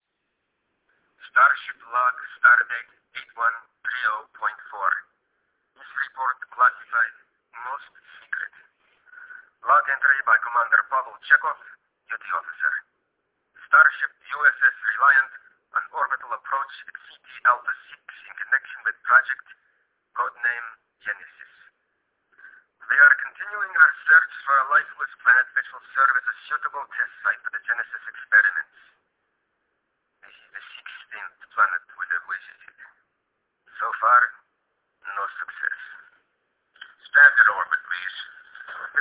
Quality is low, but the rarity of these materials makes them an absolute treasure.